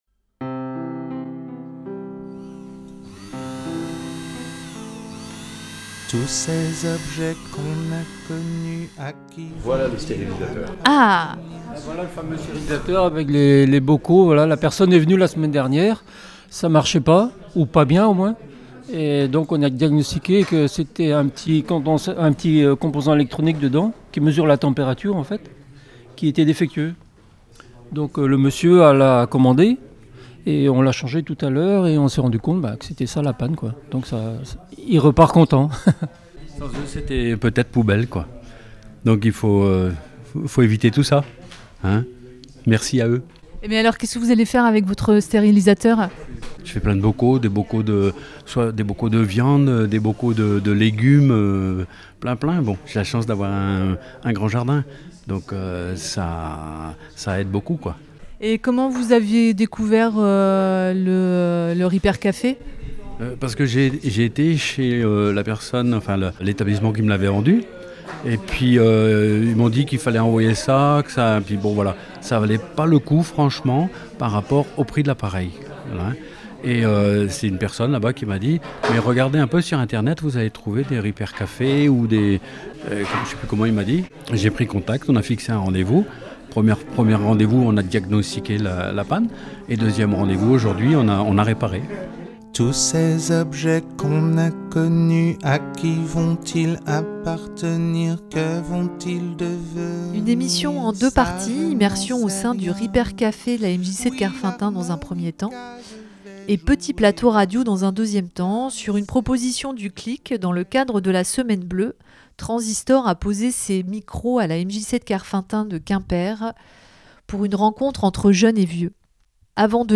Rencontres au Repair café de la MJC/MPT de Kerfeunteun dans le cadre de la semaine bleue. Un atelier radio avec différentes générations au sein du Repair café pour parler d’engagement, de vieillesse, de transmission et surtout du vivre ensemble.
Nous avons d'abord promené le micro au sein du Repair café pour saisir l'ambiance du moment, capter des sons, des paroles en lien avec cette démarche de faire durer des objets... Une fois les outils rangés, nous nous sommes installés dans l'espace jeunesse.